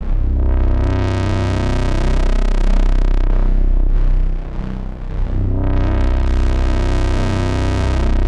and a nice lower bass sound for the sake of comparison/because i think it sounds cool